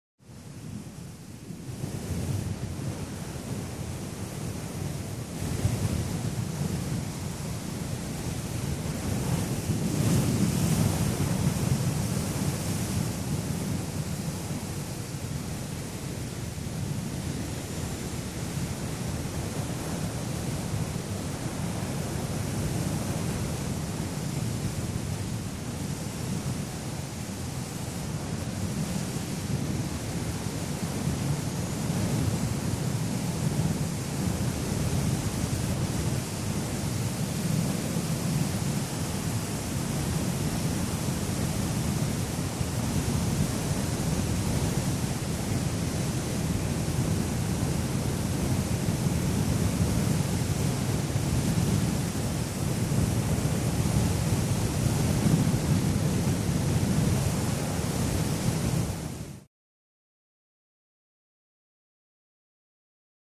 Glider, Exterior Perspective; Heavy Wind Roar In Flight With Hissy Gusts. Sounds Cold.